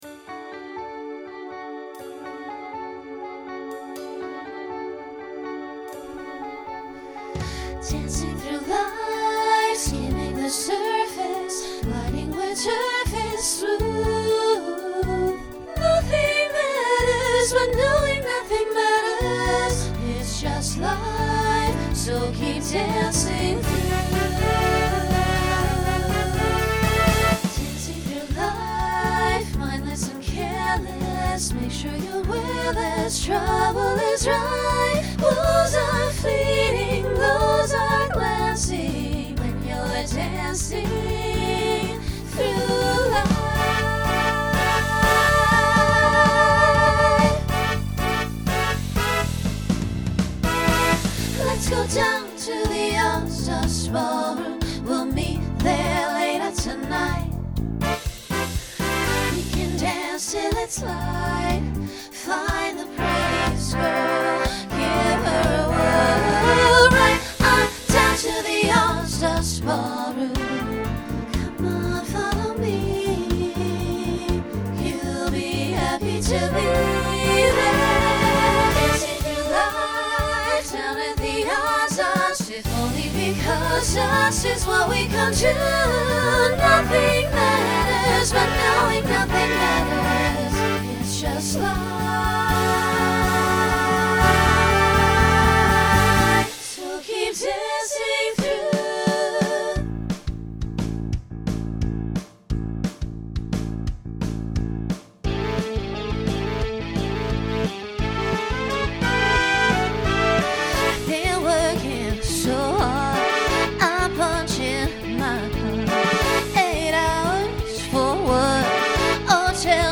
Genre Broadway/Film Instrumental combo
Voicing SSA